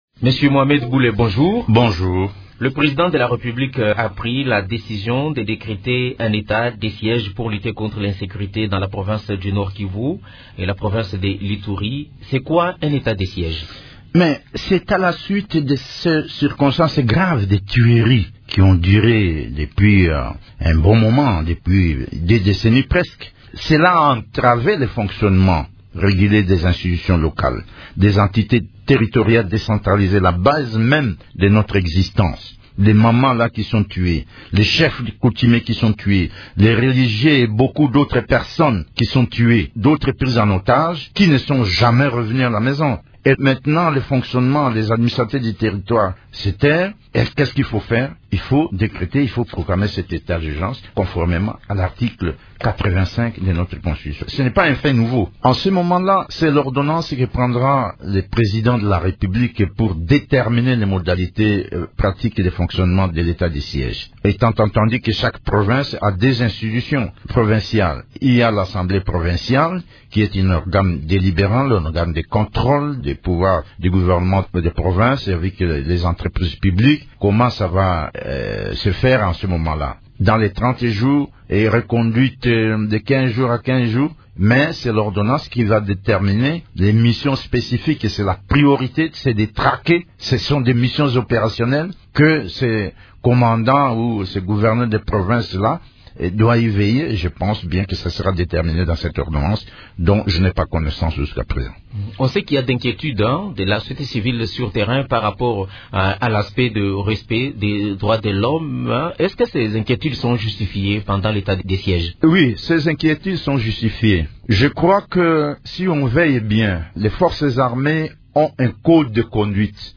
Mohamed Bule est général à la retraite, ancien vice-ministre de la Défense et député national. Il évoque le respect des droits de l’homme pendant l’état de siège ainsi que les mesures d’accompagnement pour la réussite de cette mesure prise par le chef de l’Etat en vue d’éradiquer l’insécurité dans les provinces du Nord-Kivu et Ituri.